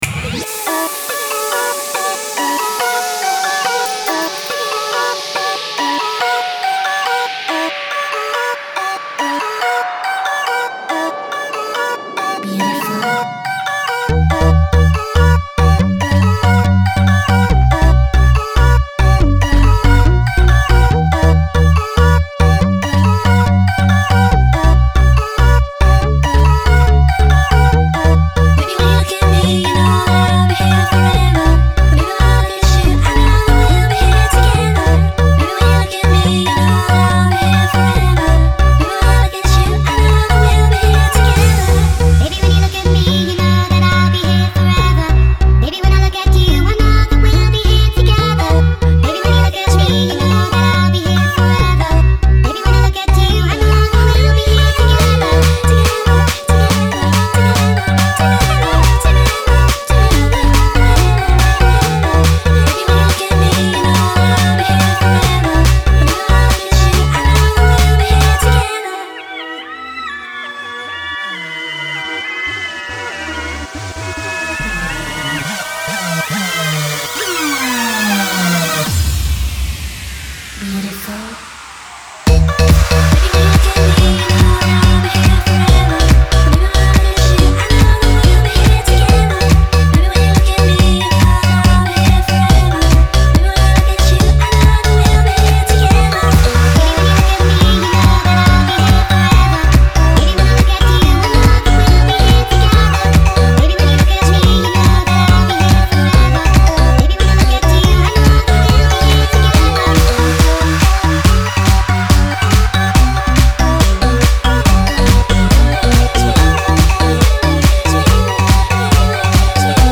Et voilà, ça devait arrivé, la DANCE est de retour.